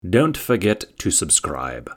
Download Male Voice sound effect for free.
Male Voice